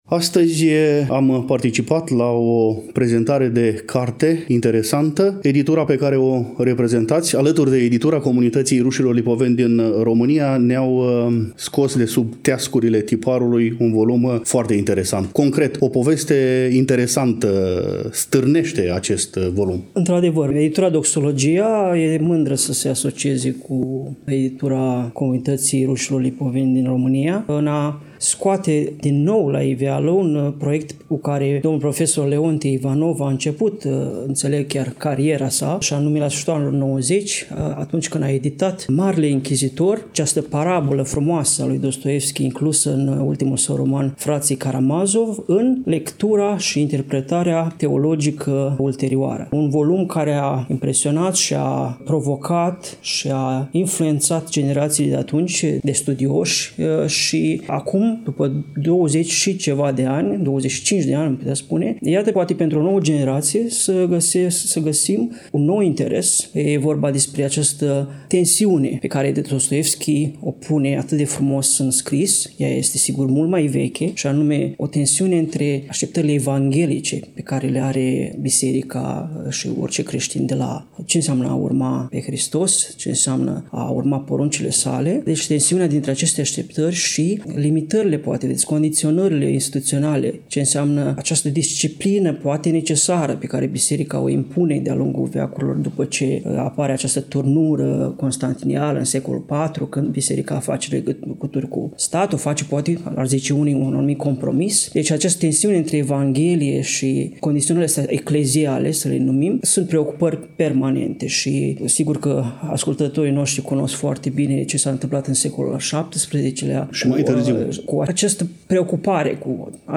În ediția de astăzi a emisiunii noastre, relatăm de la prezentarea cărții Marele Inchizitor. Dostoievski în interpretări teologico-filosofice (Konstantin Leontiev, Vladimir Soloviov, Vasili Rozanov, Serghei Bulgakov, Nikolai Berdiaev, Dmitri Merejkovski, Semion Frank, Nikolai Losski), eveniment desfășurat în ziua de joi, 14 noiembrie 2024, începând cu ora 14,  la Iași, în incinta Librăria Tafrali, corpul A al Universității „Alexandru Ioan Cuza”.